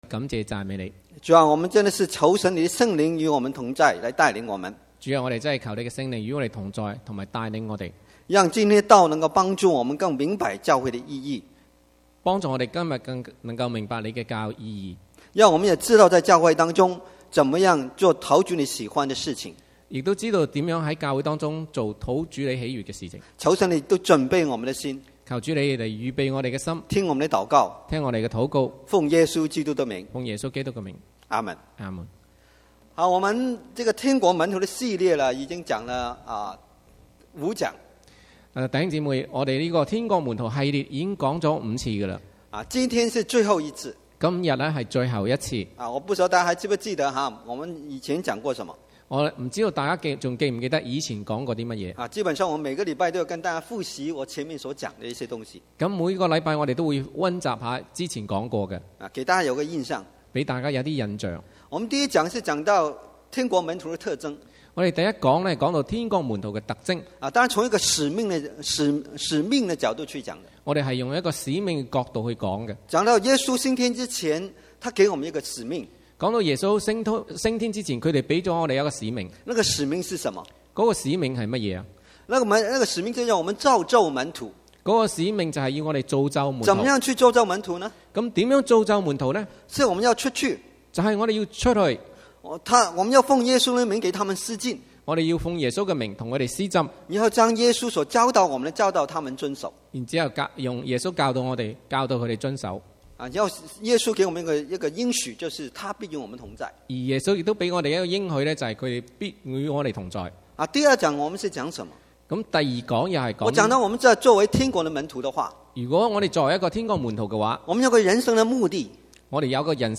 Combined Service
From Series: "Chinese Sermons"